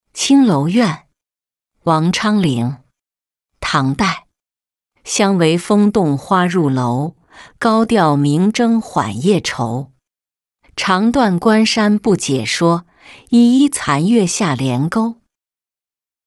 青楼怨-音频朗读